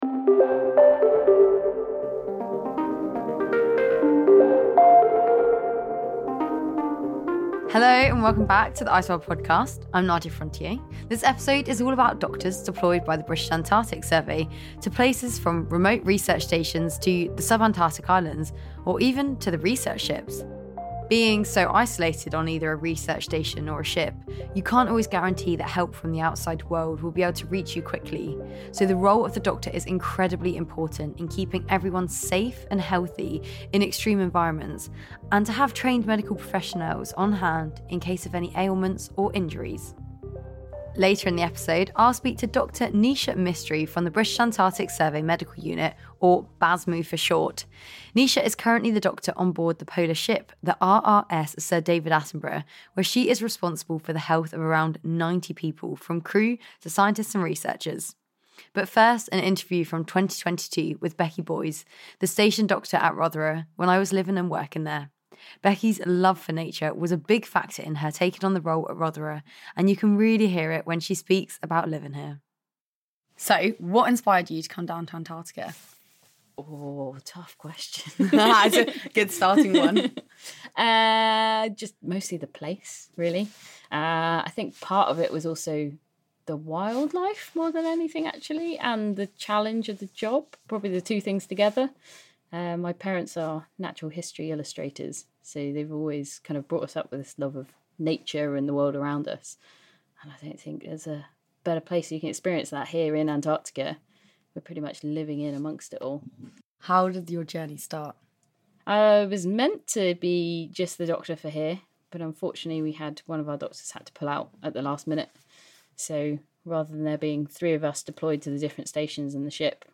From polar scientists to plumbers, ICEWORLD is a series of interviews with ordinary people who are doing extraordinary jobs in Antarctica. The team talk climate science, extreme living, expeditions and becoming a community.